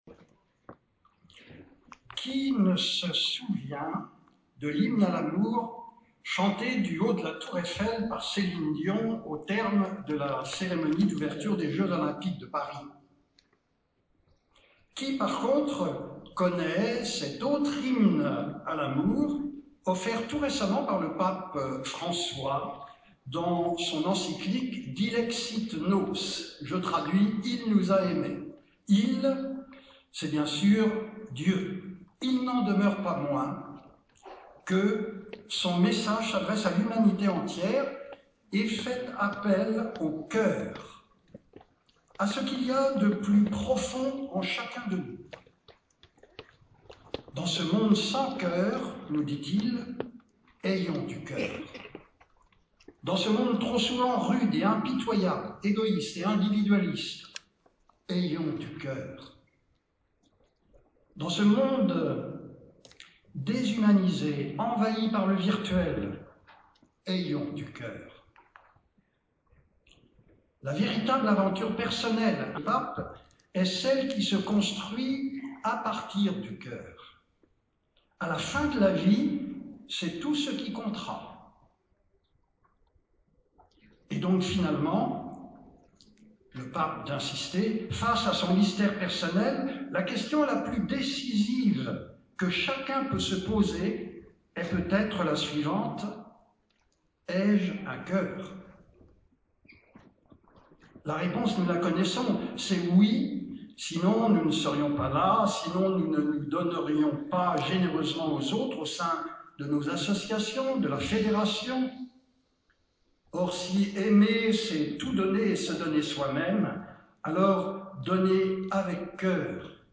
Intervention de l'aumônier lors de l'Assemblée Générale de la